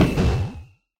Sound / Minecraft / mob / enderdragon / hit3.ogg
hit3.ogg